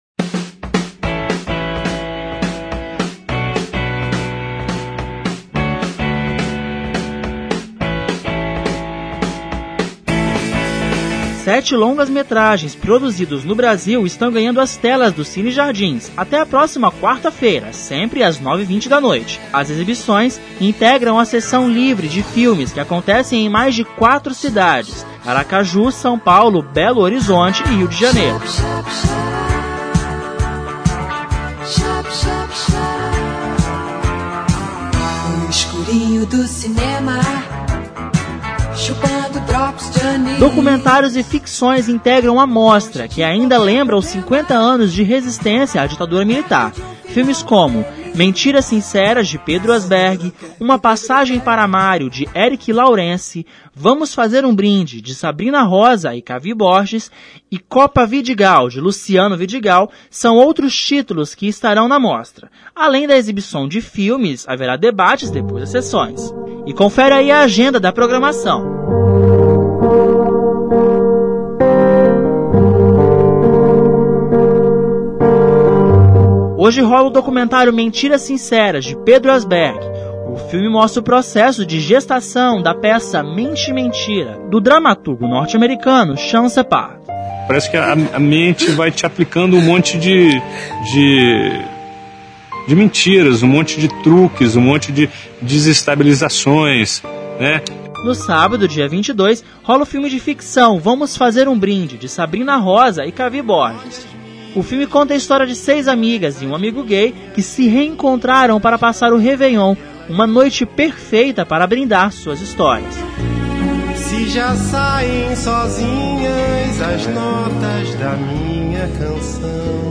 Confira mais informações na reportagem.